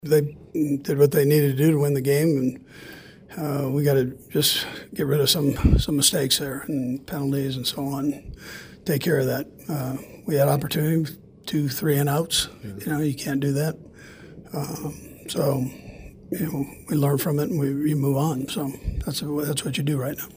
DENVER — Wil Lutz kicked five field goals, including a 35-yard game-winner as time expired Sunday, pushing the Denver Broncos past the Kansas City Chiefs 22-19 for their eighth straight victory. Chiefs head coach Andy Reid talked about the loss